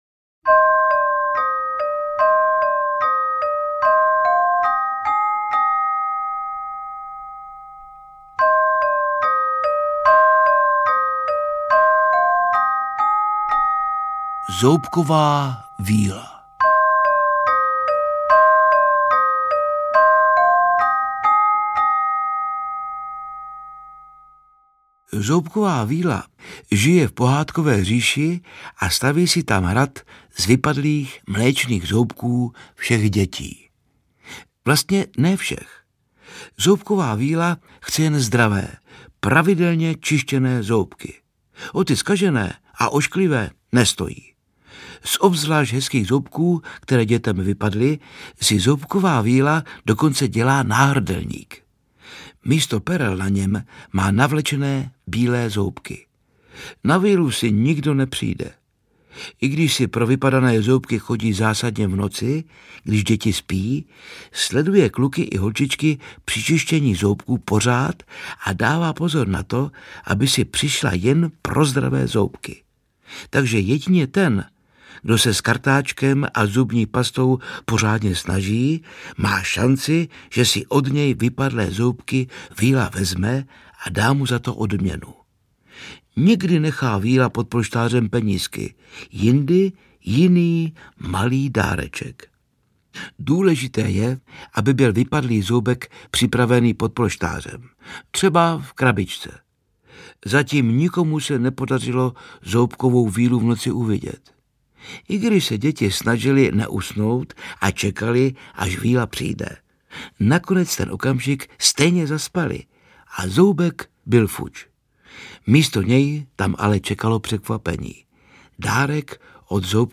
Audiokniha na pomoc všem rodičům i dětem. Zábavné pohádky a příběhy, v nichž se posluchači dozvědí, proč je důležitá péče o zoubky.